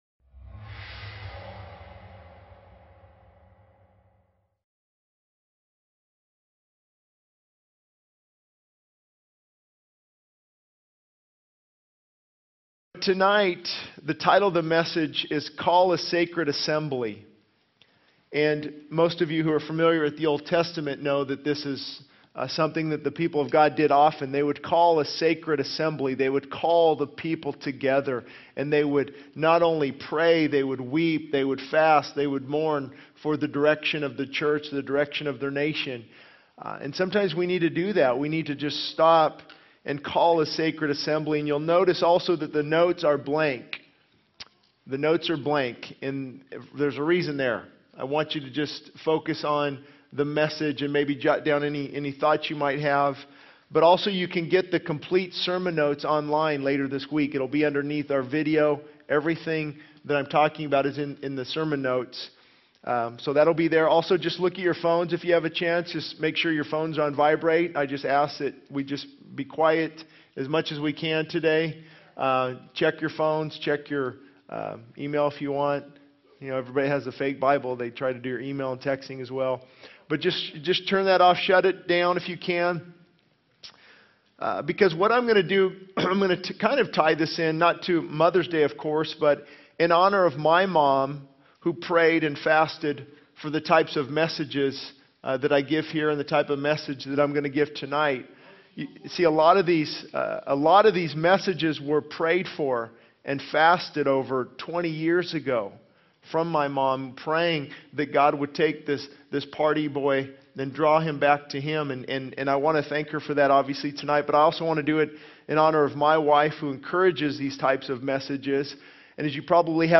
Ultimately, he urges the congregation to return to God, promising that He will pour out His Spirit upon those who seek Him earnestly.